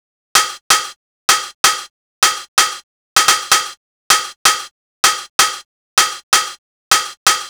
Lis Hats Loop.wav